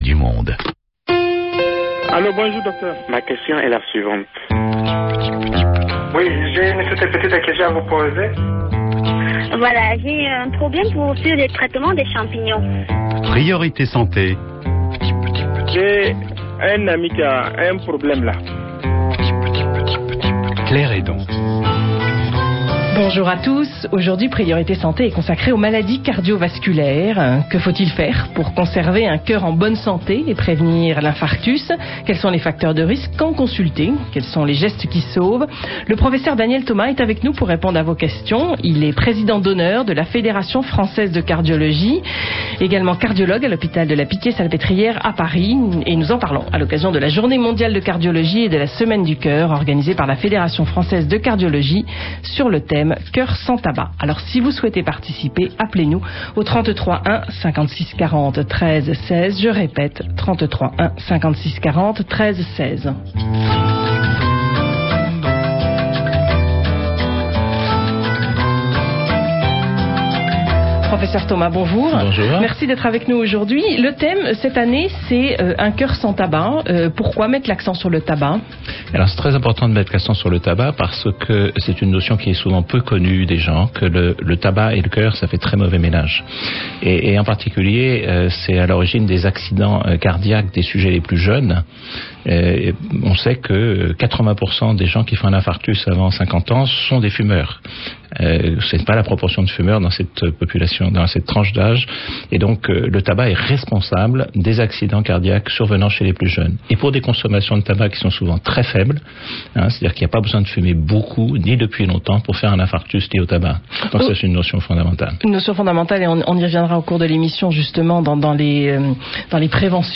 Questions des auditeurs : maladies cardiaques